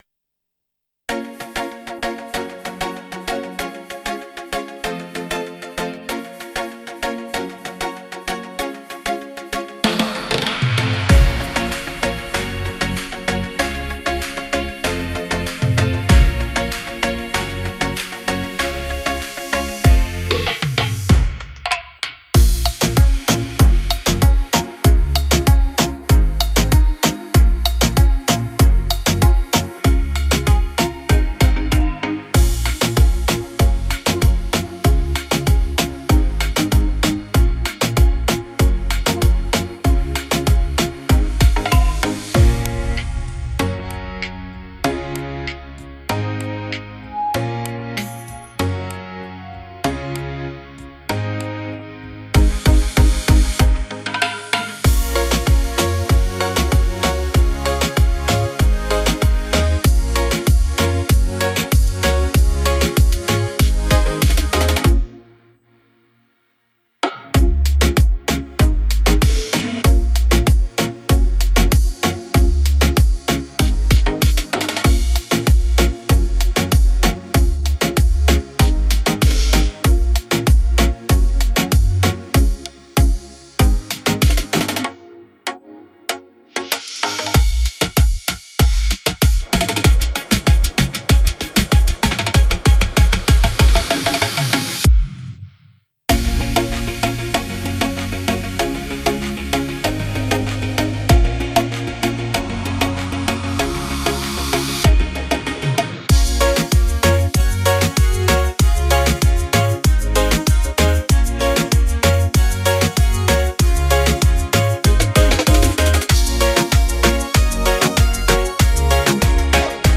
Dancehall × Reggaeton のフュージョンRiddim。BPM 96 / Key A#m
BPM:96 Key:A#m